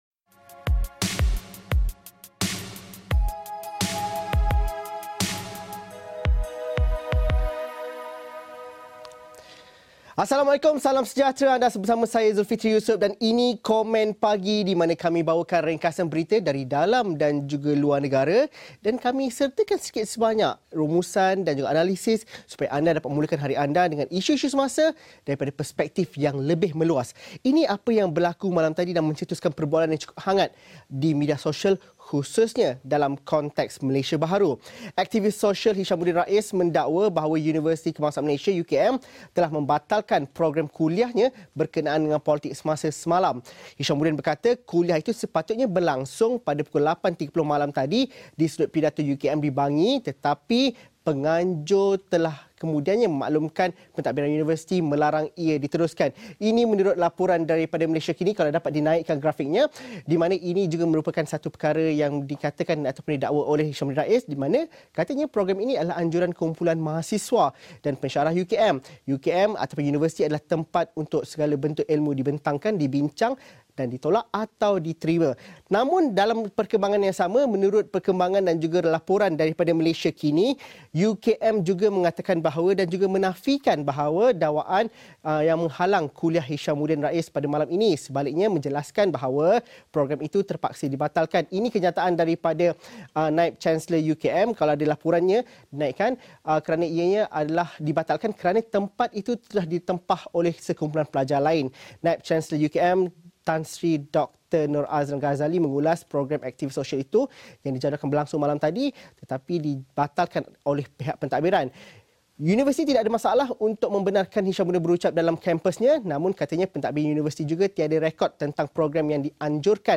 Kupasan 15 minit berita dan isu-isu semasa, dalam dan luar negara secara ringkas dan padat